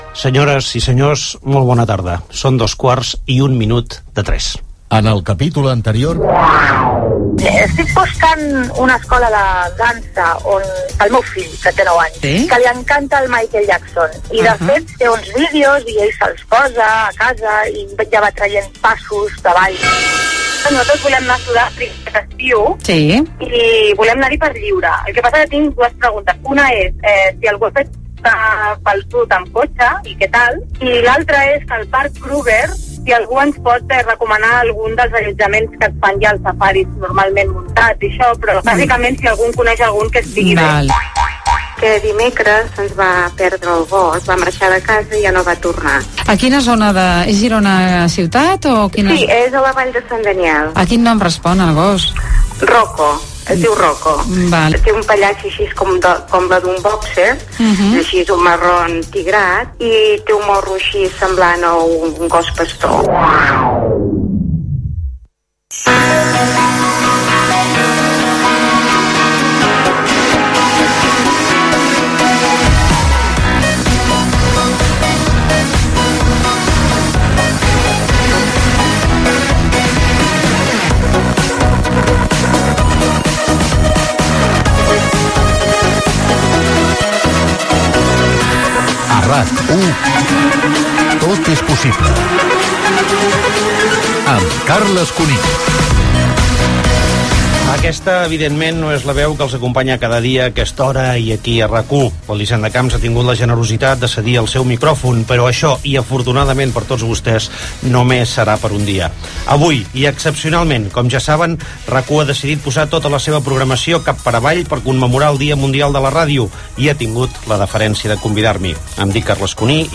Carles Cuní presenta el programa amb motiu del Dia Mundial de la Ràdio i la programació especial.